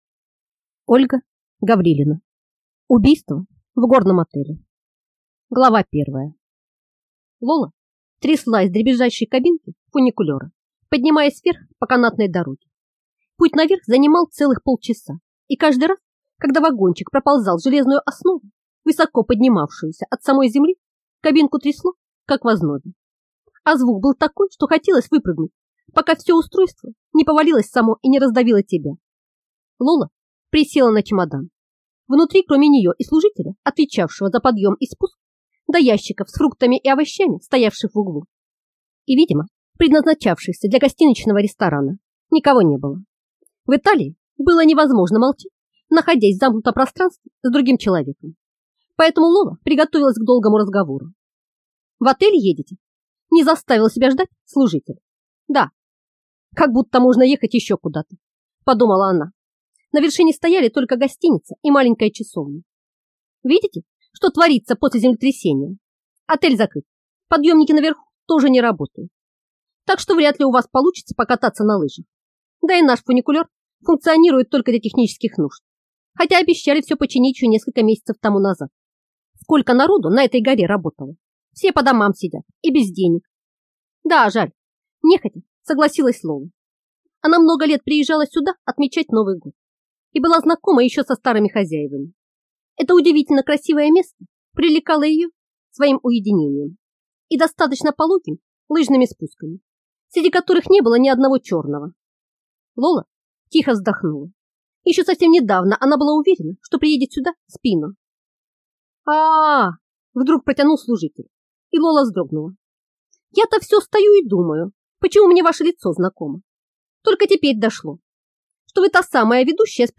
Аудиокнига Убийство в горном отеле | Библиотека аудиокниг